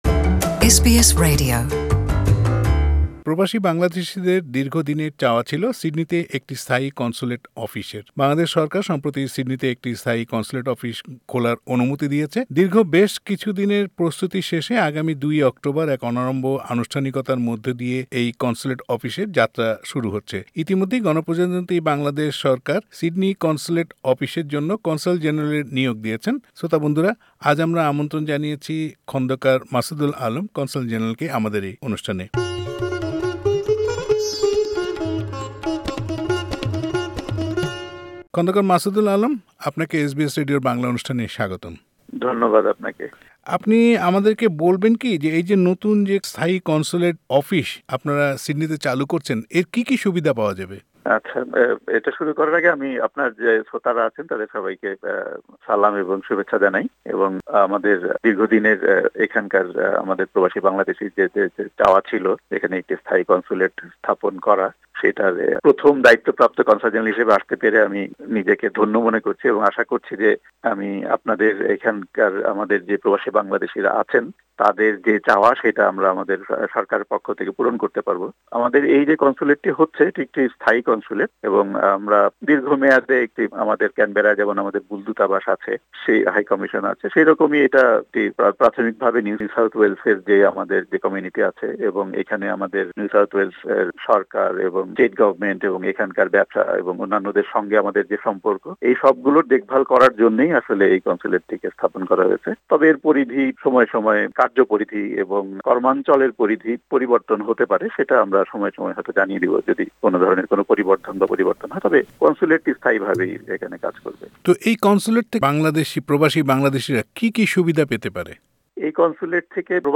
কনসাল জেনারেল খন্দকার মাসুদুল আলম কথা বলেছেন এসবিএস বাংলার সঙ্গে।